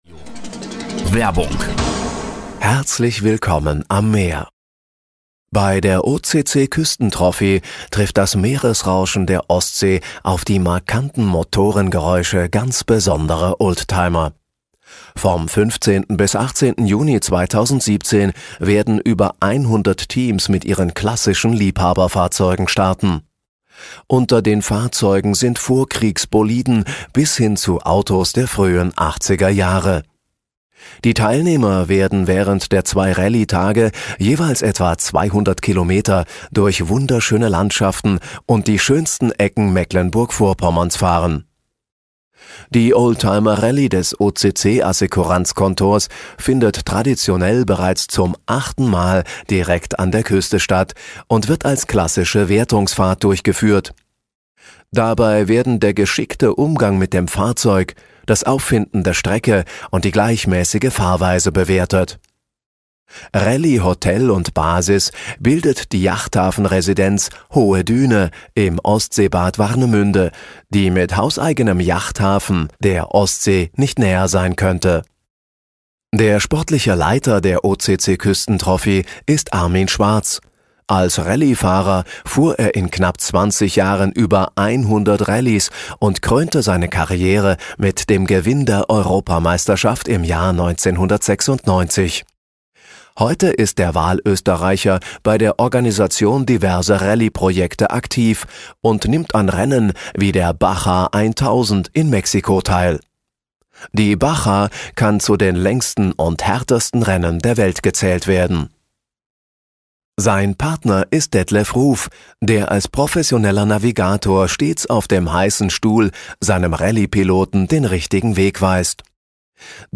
Bei der OCC-Küstentrophy trifft vom 15. bis 18. Juni 2017 trifft das Meeresrauschen der Ostsee auf die markanten Motorengeräusche ganz besonderer Oldtimer…